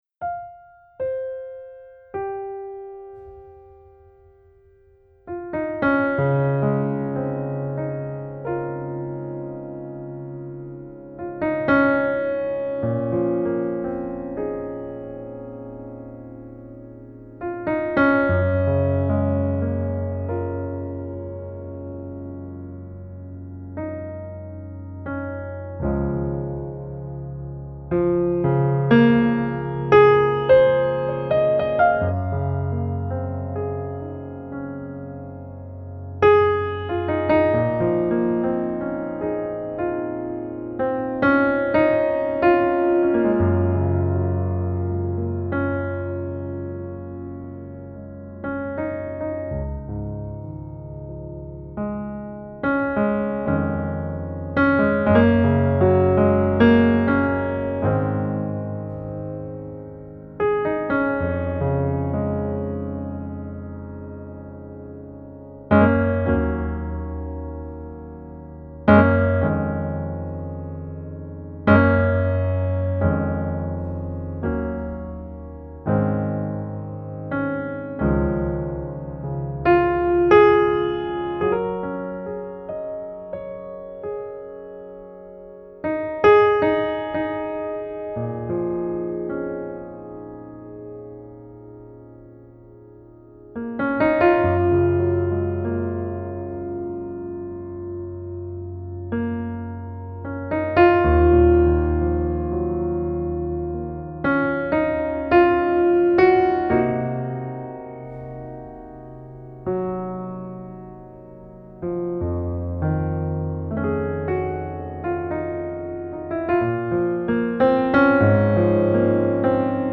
Klavierträumereien
Klaviertraeumereien.mp3